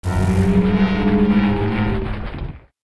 Cri de Ferdeter dans Pokémon Écarlate et Violet.